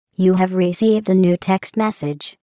I thought I'd share these cool Portal ringtones for any Portal fans out there.  8)
GlaDOS-Text.mp3